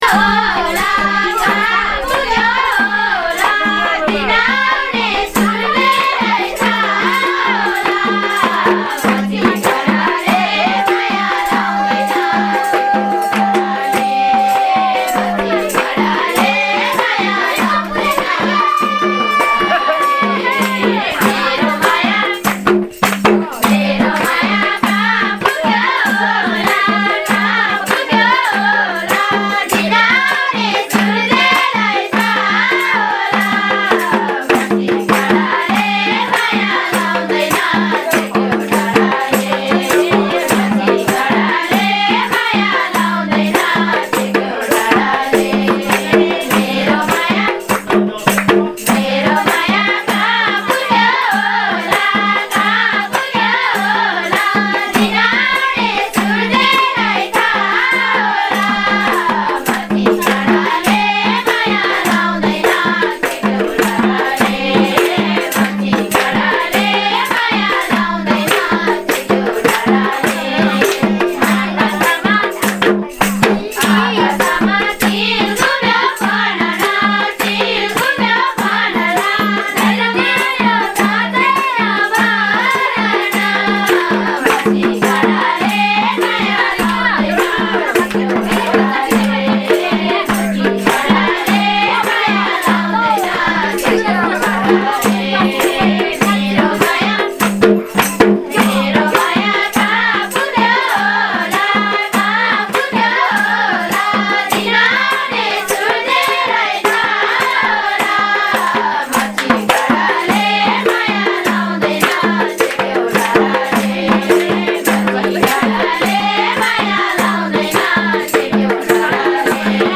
Trek Tag 7 – Nepali singing 2